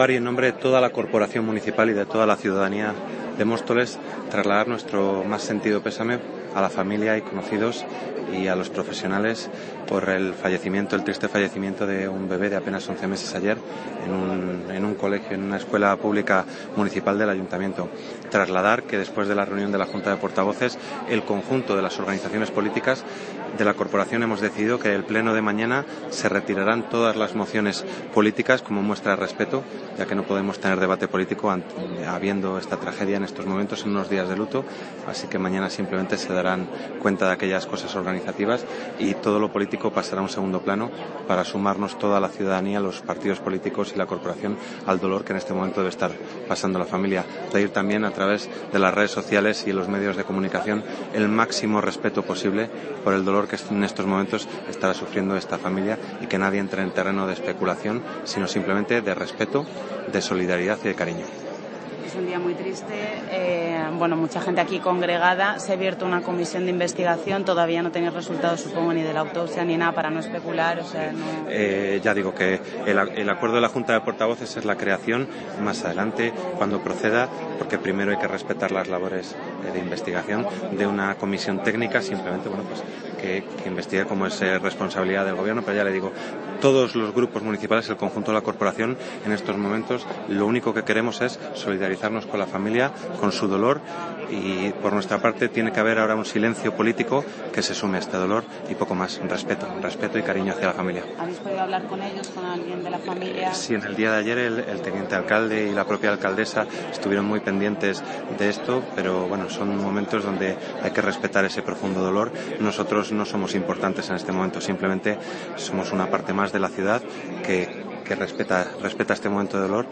Minuto silencio
Minuto silencio.mp3